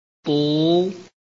臺灣客語拼音學習網-客語聽讀拼-海陸腔-單韻母
拼音查詢：【海陸腔】bu ~請點選不同聲調拼音聽聽看!(例字漢字部分屬參考性質)